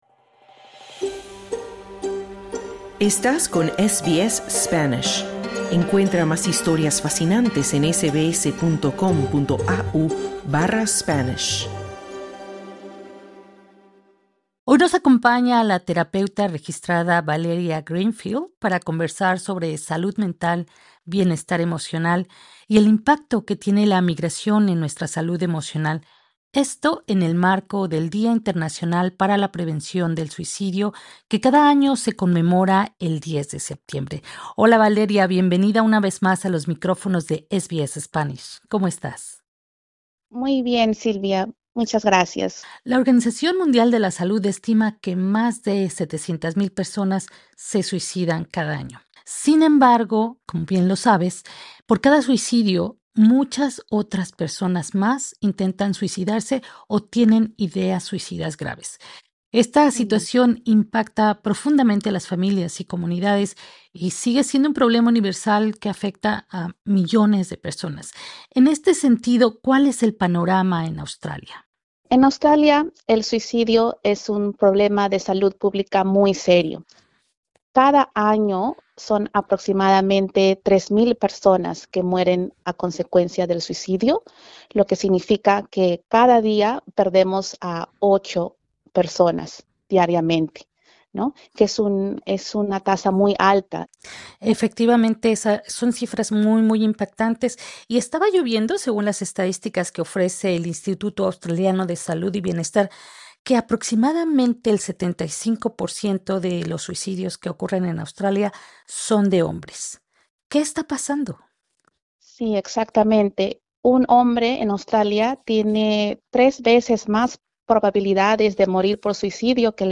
El suicidio es la causa principal de muerte entre los australianos de 15 a 24 años, de ahí la importancia de abordar el tema en nuestros círculos sociales. En el marco del Día Mundial para la Prevención del Suicidio, que se conmemora el 10 de septiembre, conversamos sobre el tema con una especialista.